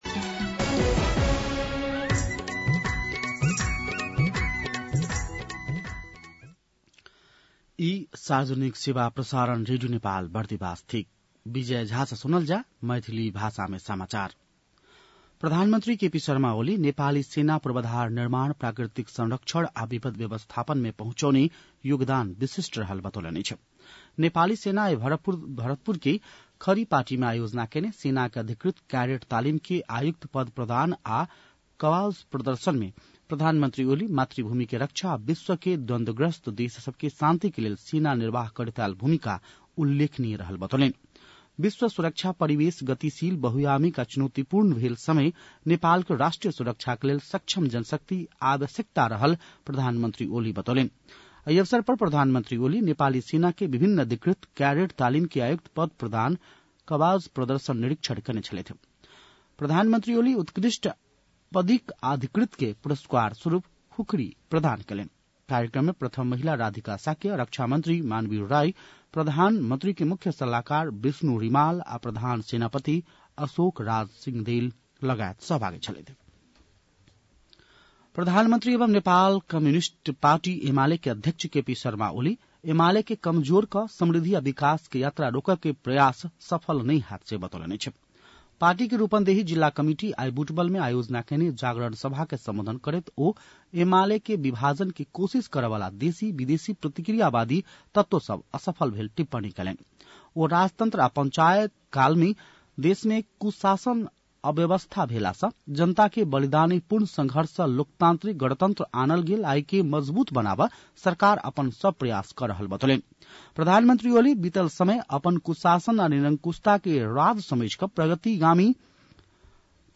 मैथिली भाषामा समाचार : २९ चैत , २०८१
Maithali-news-12-29.mp3